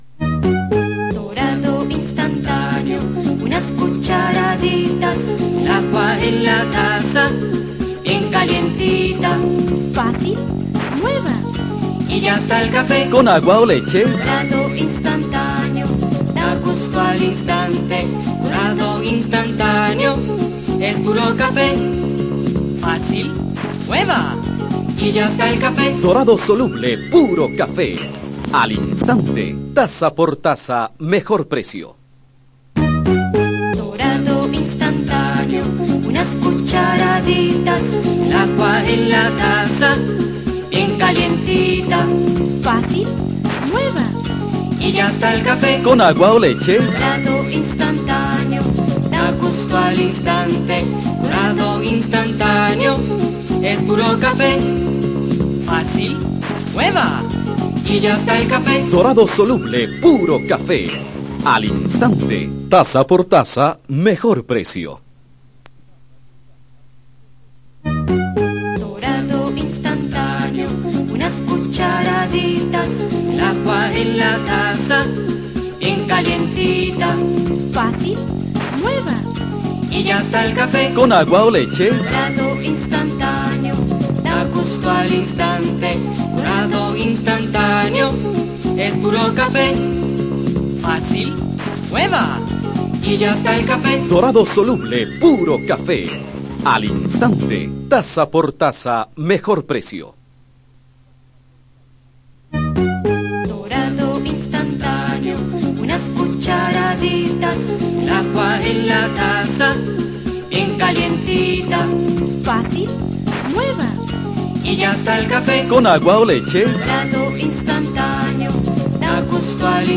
Cuña de radio sobre Café Dorado
Notas: Casete de audio y digital